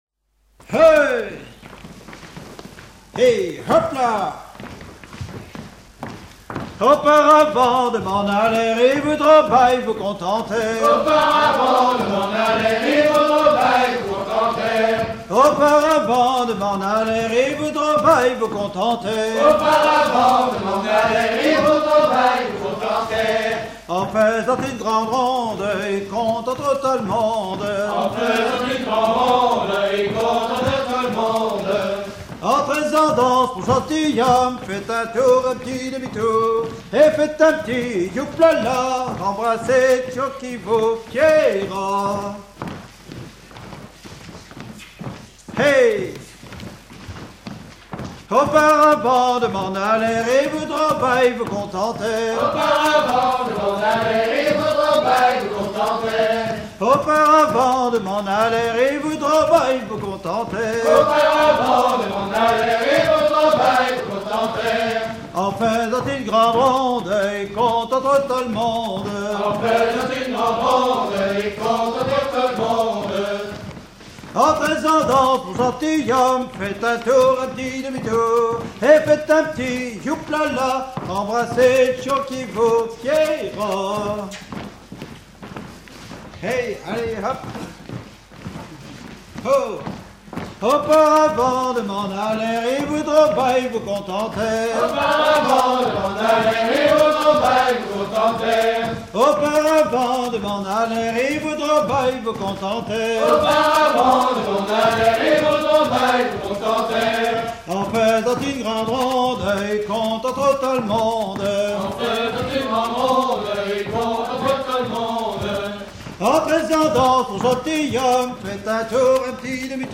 danse : ronde à embrasser
Dix danses menées pour des atelirs d'apprentissage
Pièce musicale inédite